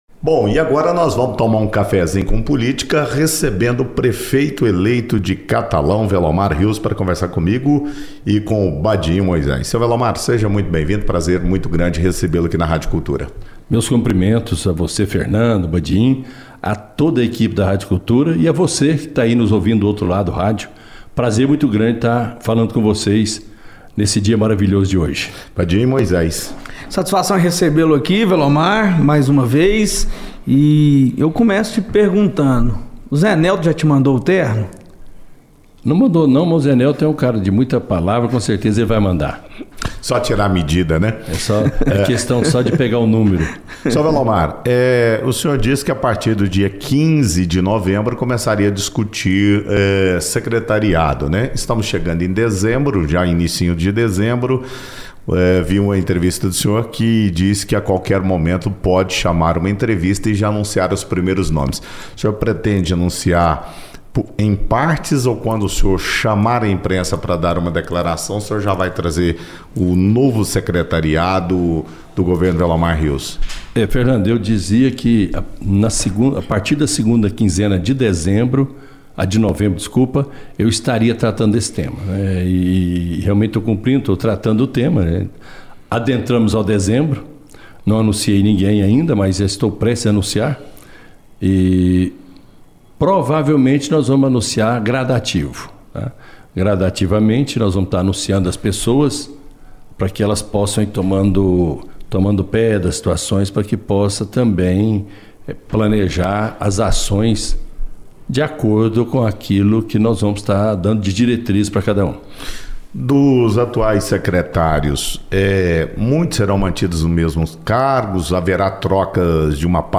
O prefeito eleito de Catalão, Velomar Rios, participou de uma entrevista na Rádio Cultura FM, onde abordou diversos temas relacionados à sua futura gestão, incluindo a formação do secretariado, continuidade administrativa e estrutura organizacional do governo municipal.
ENTREVISTA-VELOMAR-PREFEITO-ELEITO-PRIMEIRA-PARTE.mp3